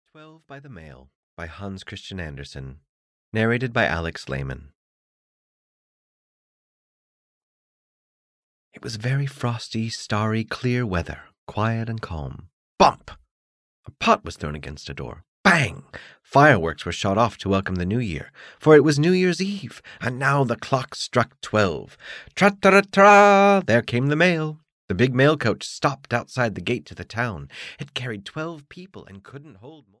Twelve by the Mail (EN) audiokniha
Ukázka z knihy